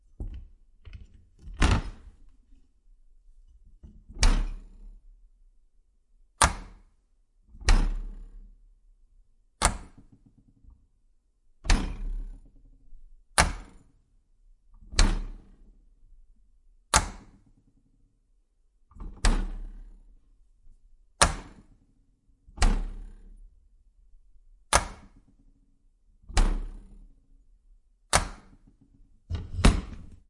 随机 "镶嵌玻璃门的木质橱柜开合点击率2
描述：门木柜与镶嵌玻璃打开close clicks2.flac
Tag: 点击 打开 关闭 木材 玻璃 橱柜 镶嵌